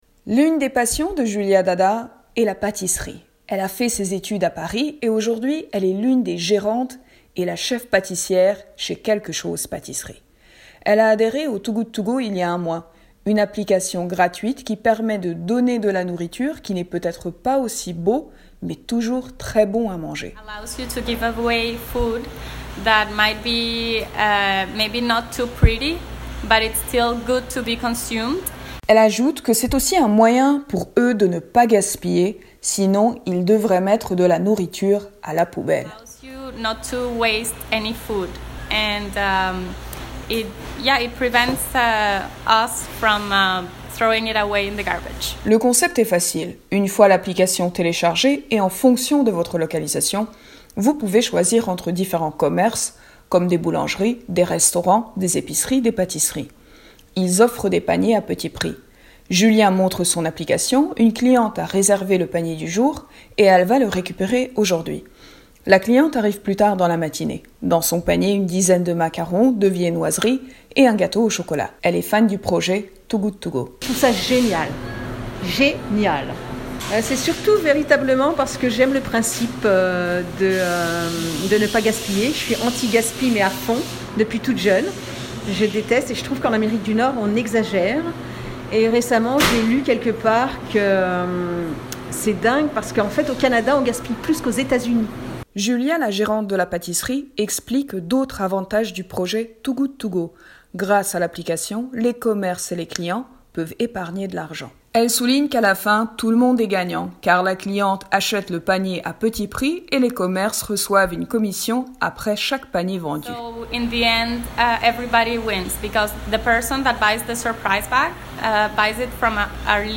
Reportage-To-Good-to-Go-IJL.mp3